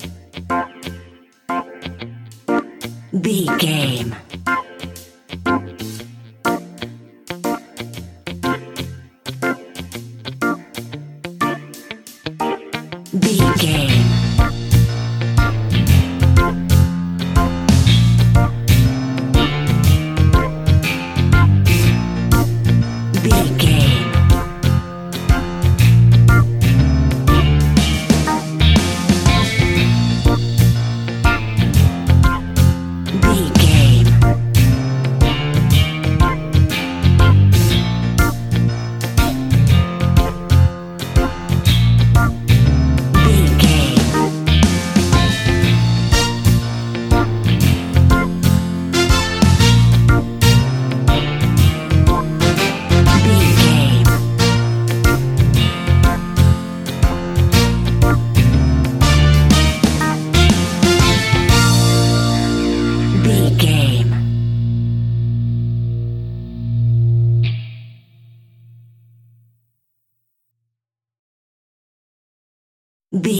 Hot summer sunshing reggae music for your next BBQ!
Ionian/Major
B♭
Slow
dub
laid back
chilled
off beat
drums
skank guitar
hammond organ
percussion
horns